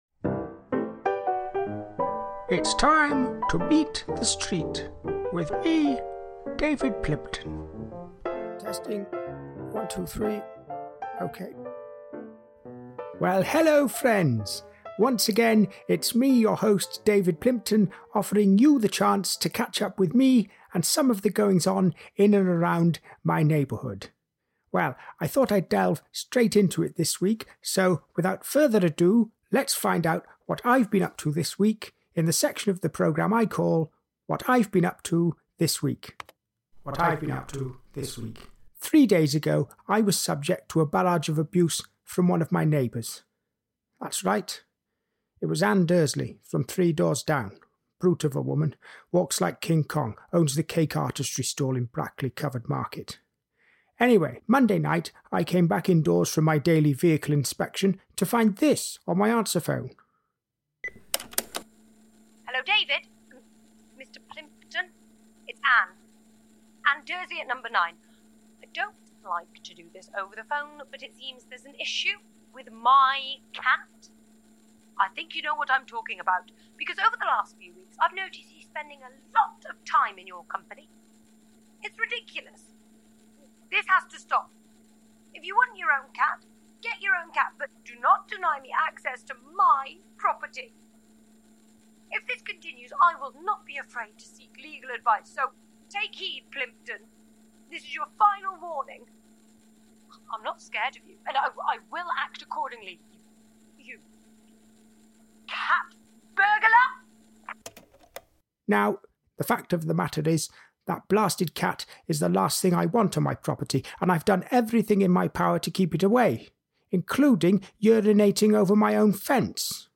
Featuring comedian/writer
comedian/podcaster